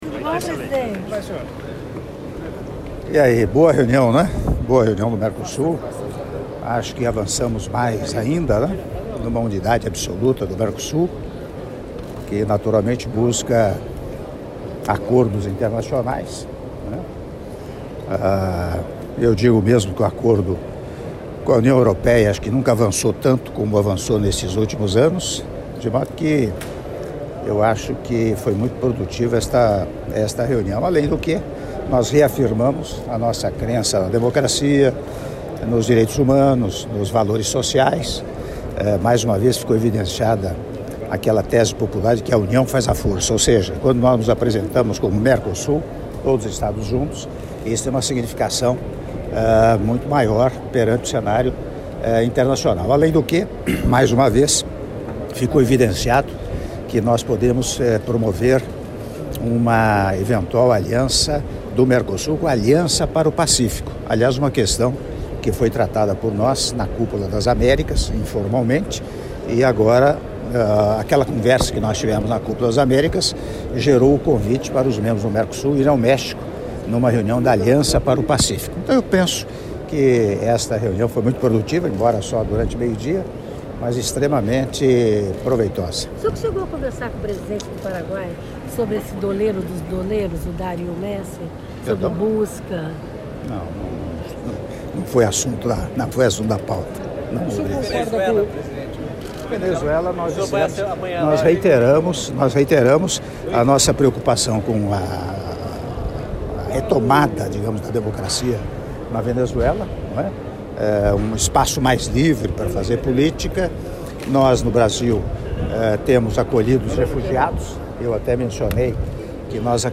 Áudio da Entrevista Coletiva concedida pelo Presidente da República, Michel Temer, após Almoço em Homenagem aos Presidentes dos Estados Partes do Mercosul, Estados Associados e Convidados Especiais, oferecido pelo Senhor Horacio Cartes, Presidente da República do Paraguai - Assunção/Paraguai - (04min16s)